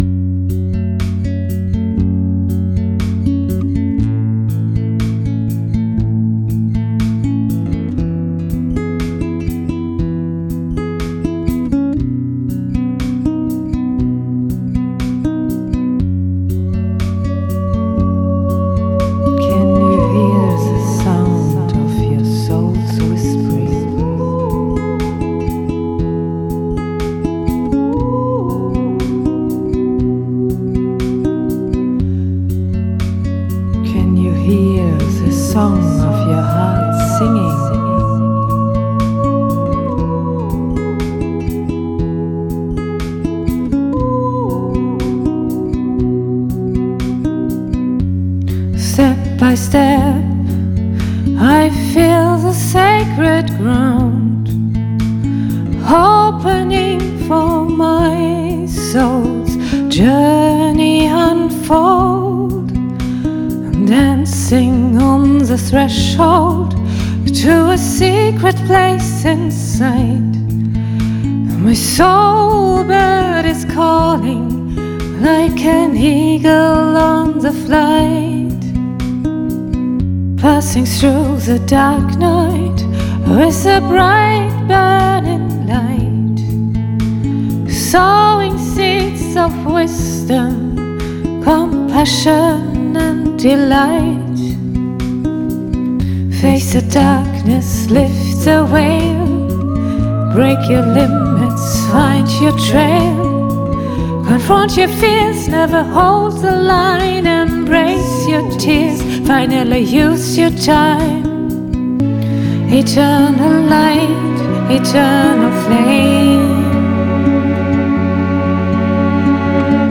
Wir haben sie bereits an der Geige, Gitarre und am Klavier erlebt – jedes Mal beeindruckt sie mit ihrer besonderen, einfühlsamen und intensiven Art zu singen.